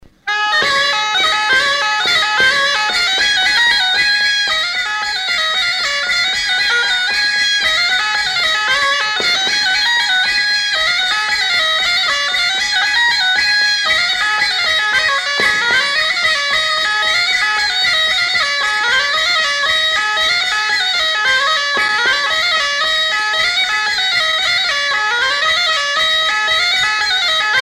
Genre : morceau instrumental
Instrument de musique : cabrette ; grelot
Danse : bourrée